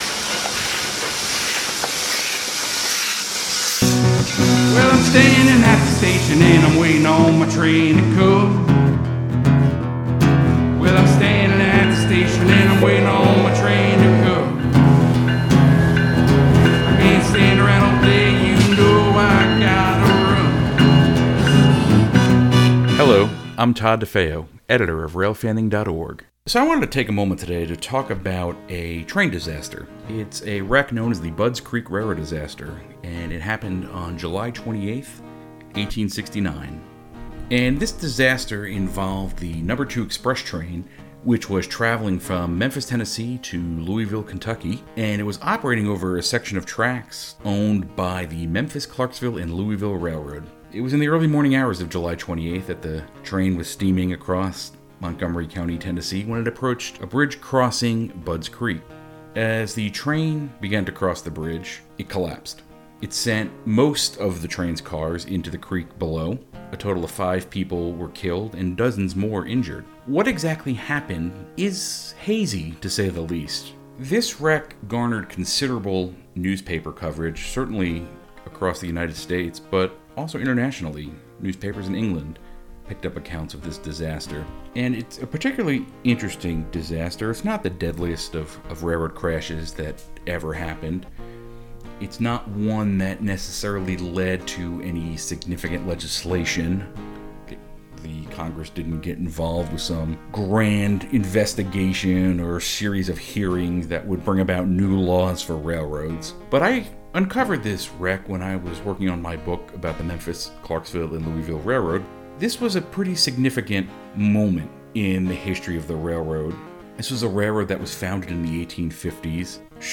Sound Effects Diesel Horn: Recorded at the Southeastern Railway Museum on Nov. 14, 2020. Steam Train: 1880s Train, recorded Sept. 12, 2020, in Hill City, South Dakota. Show Notes This previously unreleased podcast was edited from recordings made in July 2019.